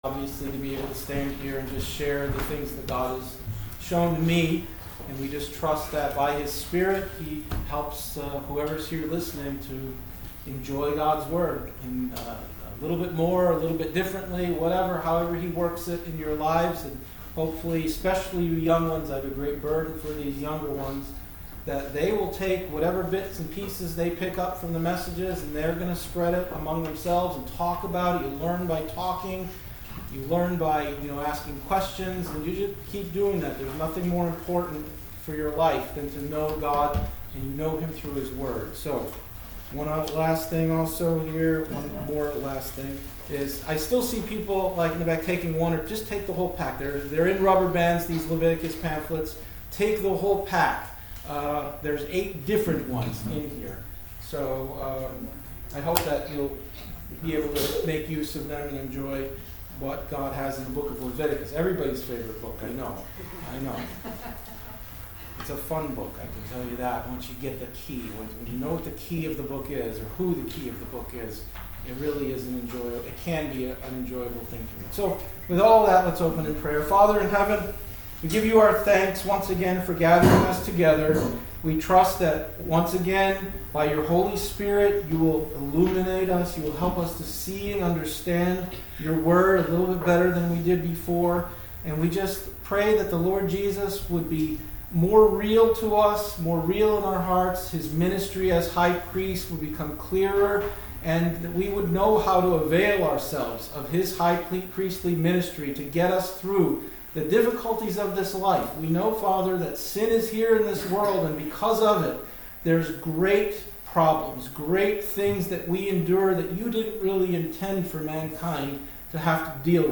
Spring Conference P.4 Passage: Gen 14 , Psalm 110 , Hebrew Service Type: Sunday Afternoon « 05.04.25